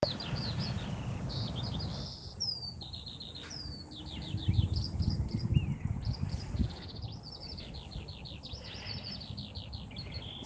Abendliche Radtour
Außerdem habe ich das erste Mal in diesem Jahr die Feldlerche singen hören: